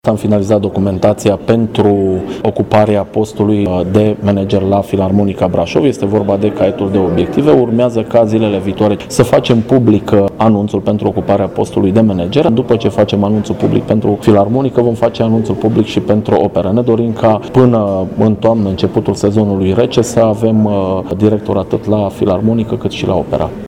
Prima va fi Filarmonica Braşov, care ar putea avea, până în luna octombrie, un manager, stabilit prin concurs, după care va urma concursul de la Operă, după cum a precizat viceprimarul Braşovului, Mihai Costel: